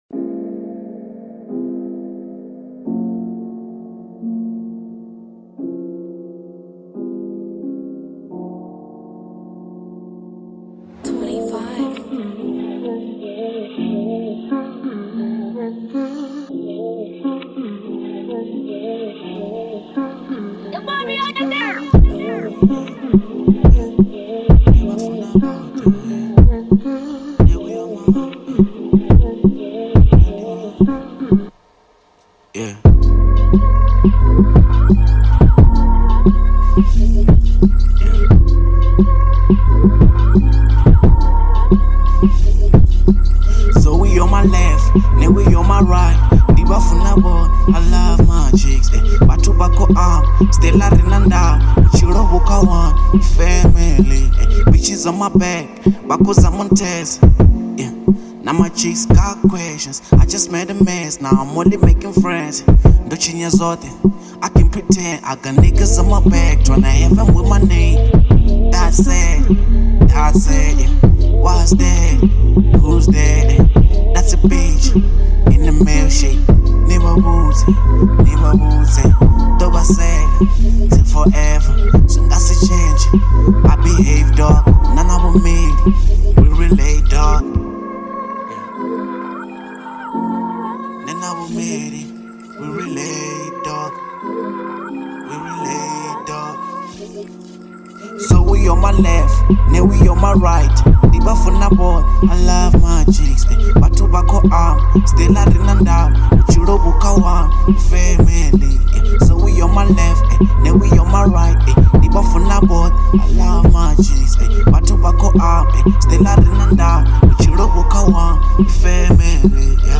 02:43 Genre : Venrap Size